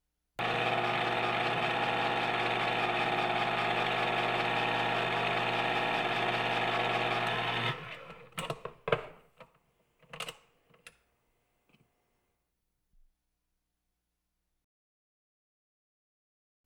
household
Electric Can Opener in Operation